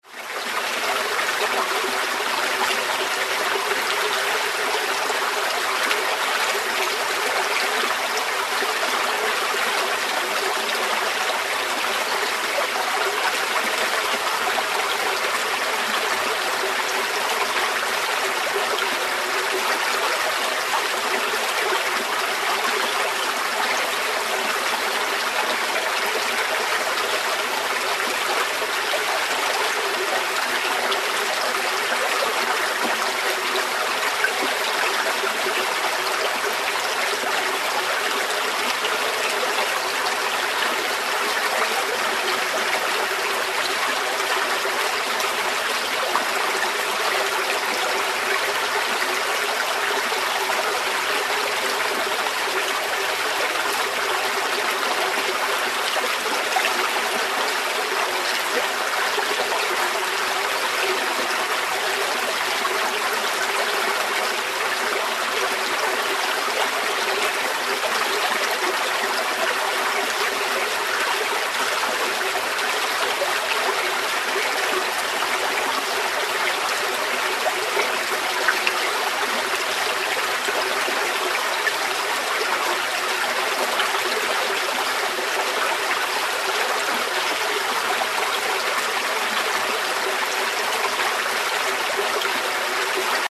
Звуки журчания ручья